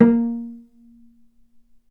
healing-soundscapes/Sound Banks/HSS_OP_Pack/Strings/cello/pizz/vc_pz-A#3-ff.AIF at 48f255e0b41e8171d9280be2389d1ef0a439d660
vc_pz-A#3-ff.AIF